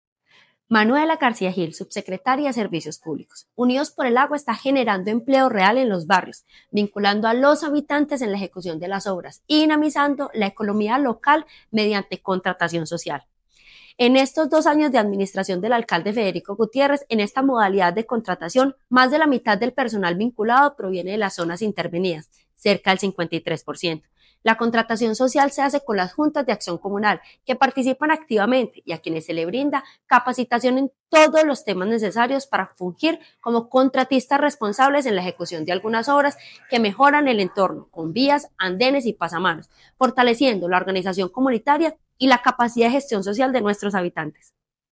Declaraciones de la subsecretaria de Servicios Públicos, Manuela García Gil El programa Unidos por el Agua genera empleo real en los barrios, vinculando a los habitantes en la ejecución de las obras y dinamizando la economía local mediante contratación social.
Declaraciones-de-la-subsecretaria-de-Servicios-Publicos-Manuela-Garcia-Gil-1.mp3